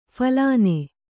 ♪ phalāne